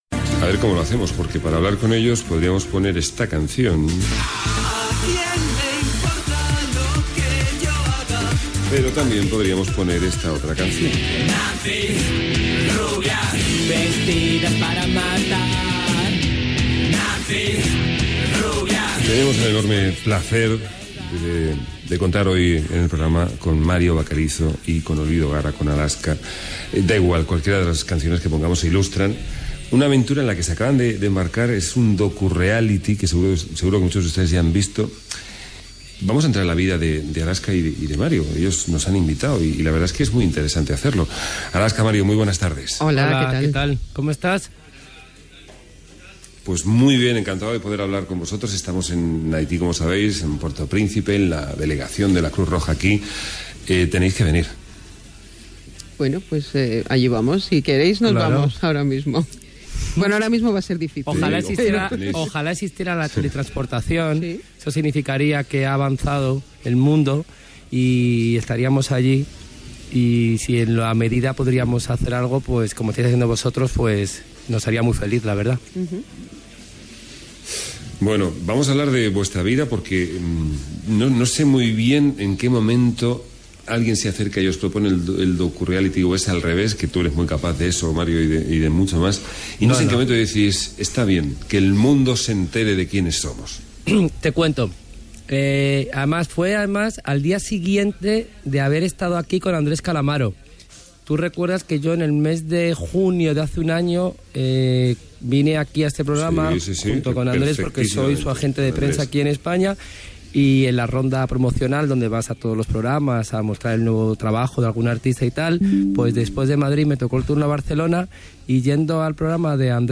Fragment d'una entrevista a Mario Vaquerizo i Olvido Gara (Alaska) que havien estrenat un "docureality". Programa emès des de la delegació de Creu Roja a Puerto Príncipe, Haití.
Entreteniment